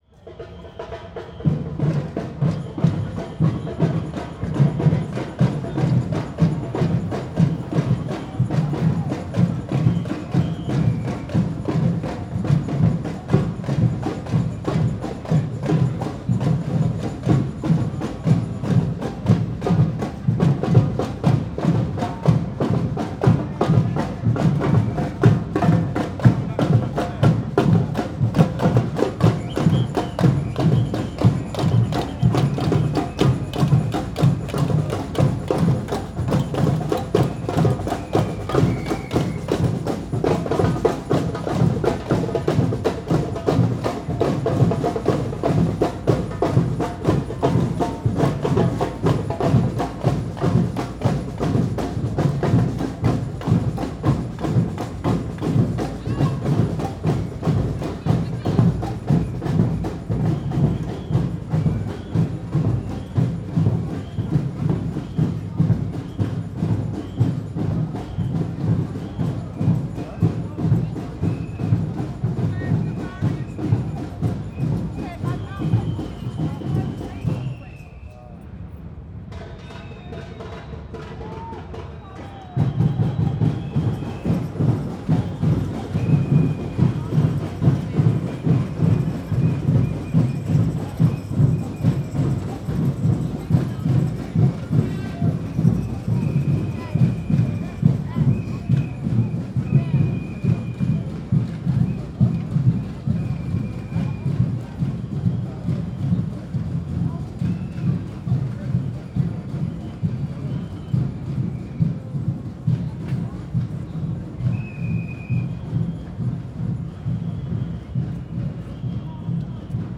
streetparty1.R.wav